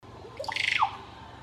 Conoto Aceituna (Psarocolius angustifrons)
Nombre en inglés: Russet-backed Oropendola
Condición: Silvestre
Certeza: Observada, Vocalización Grabada